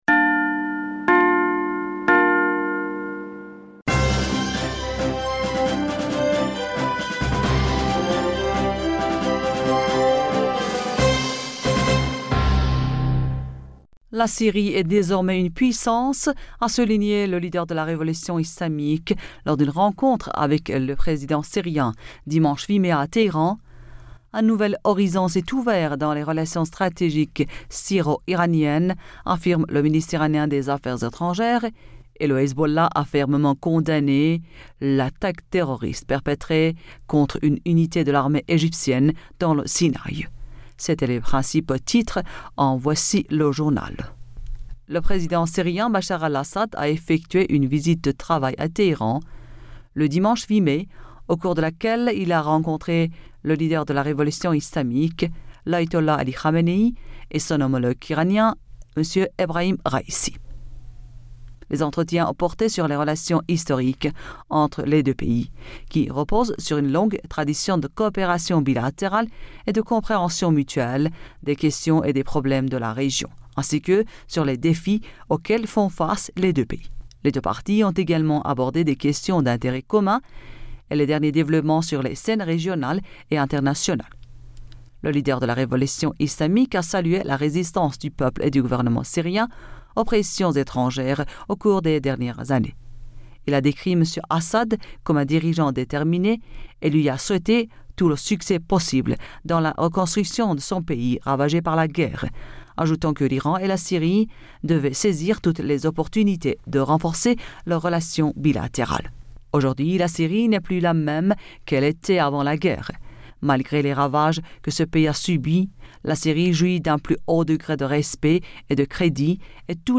Bulletin d'information Du 09 Mai 2022